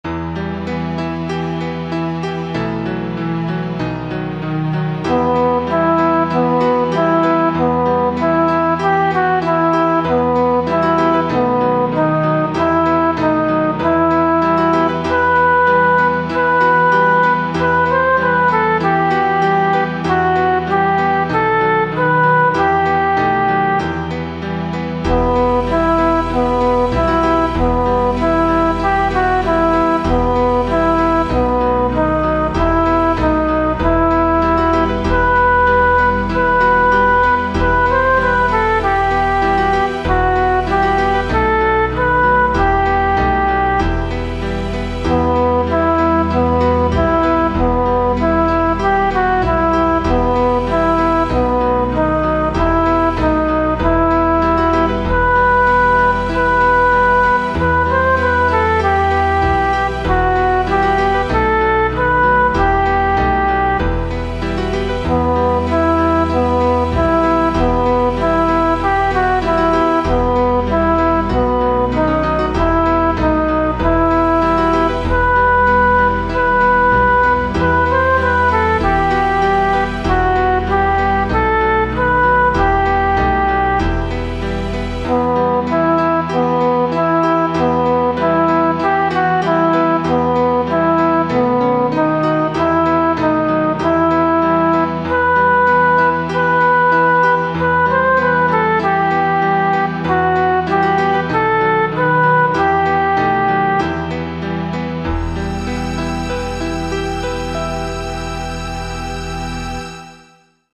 This is a chant to an Austrian tune.